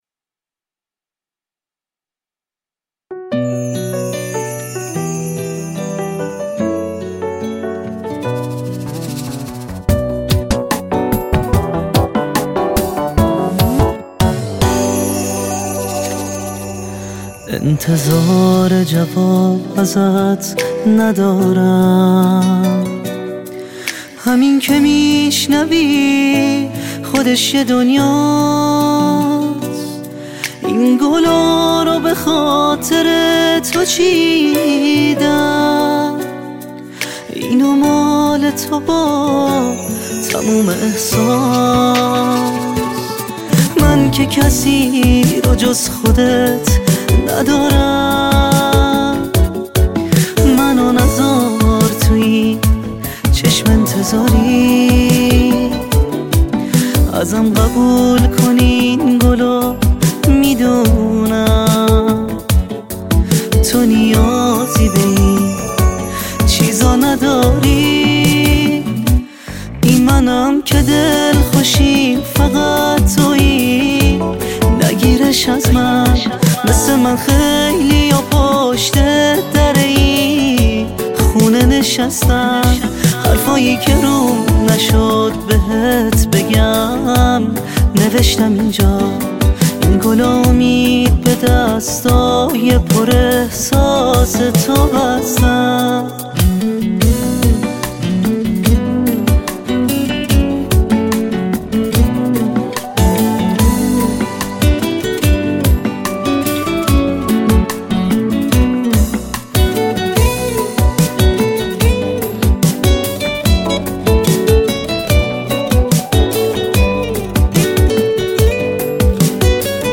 آروم و احساسی